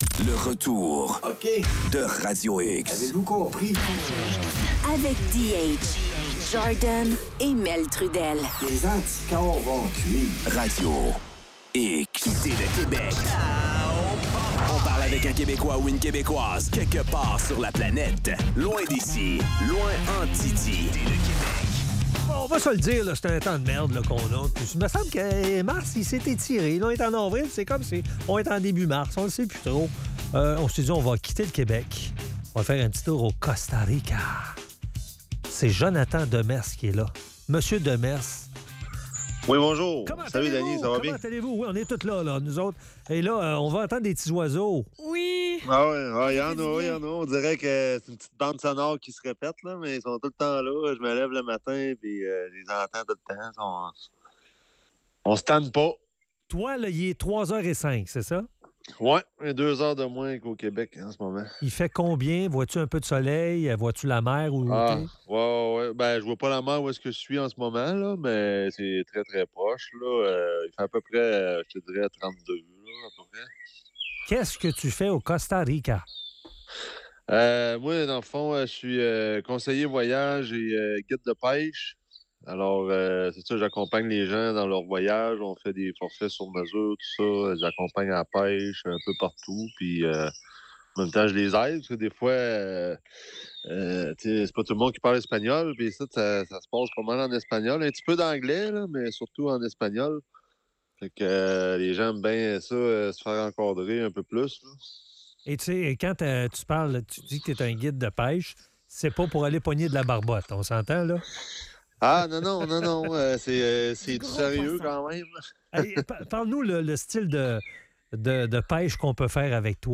Entrevue
en direct du Costa Rica.